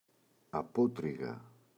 απότρυγα [a’potriγa] – ΔΠΗ